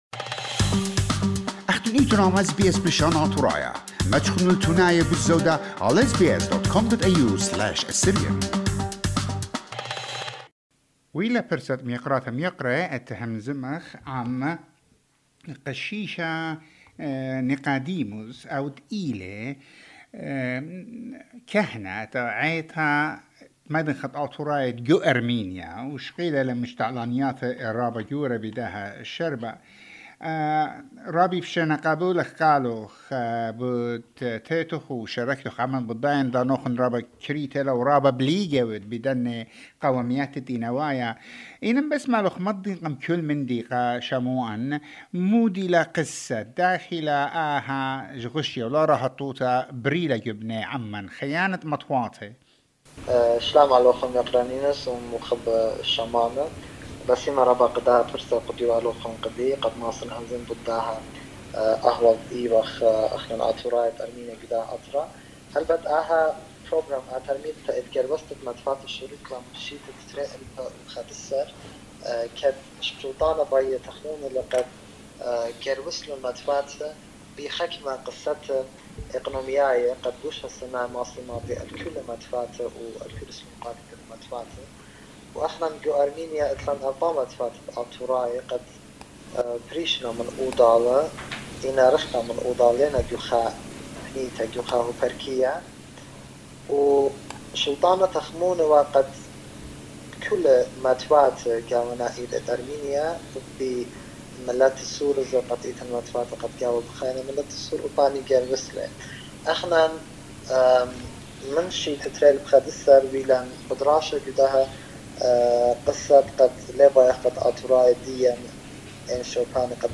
Few hours after this interview, the Armenian parliament passed the motion and voted for the Amalgamation, saying that this move will be for the better for all villages.